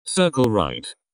Tags: voice control robot